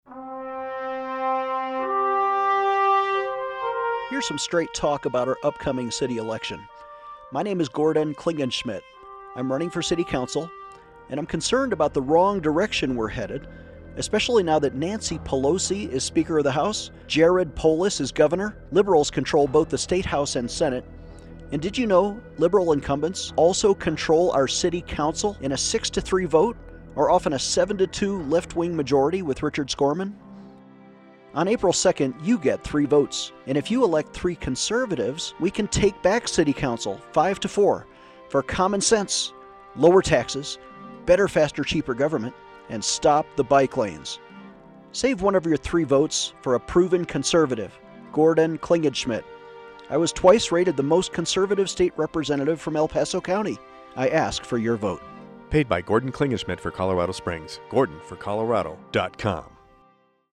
New radio ads on KVOR and KRDO.